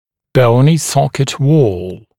[‘bəunɪ ‘sɔkɪt wɔːl][‘боуни ‘сокит уо:л]костная стенка зубной ячейки